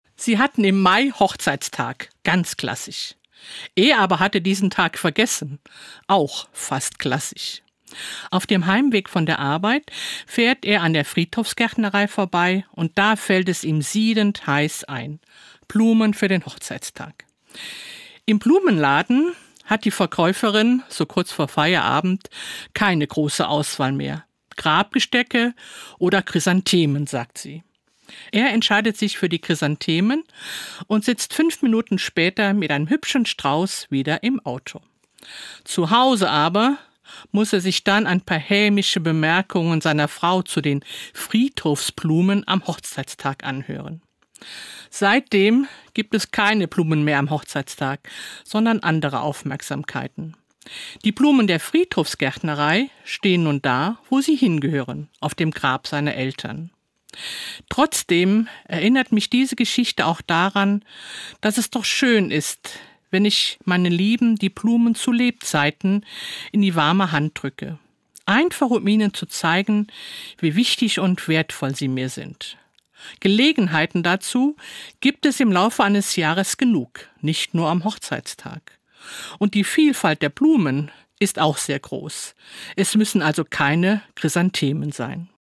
Das christliche Wort zum Alltag